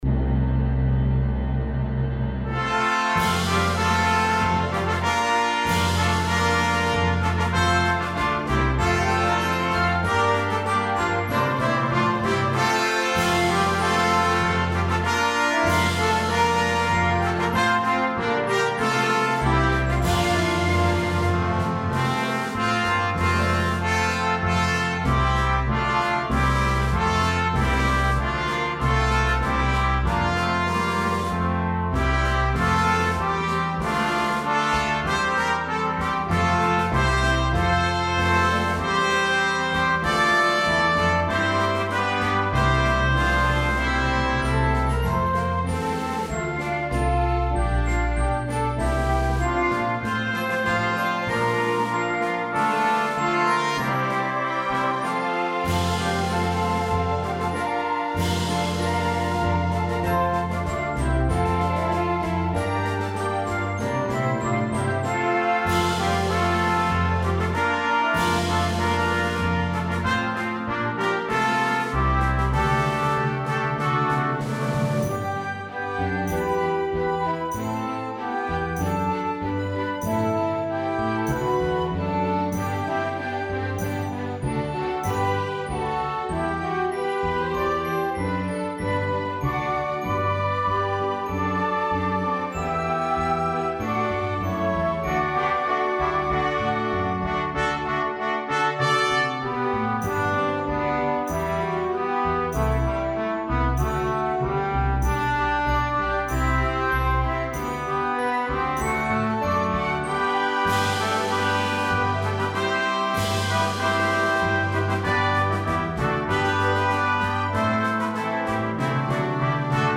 Hymn arrangement
for 9 Plus orchestra – with reduced instrumentation.
A bold introduction lead to a triumphant arrangement